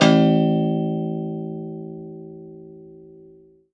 53p-pno01-A-1.wav